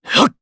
Clause_ice-Vox_Attack2_jp.wav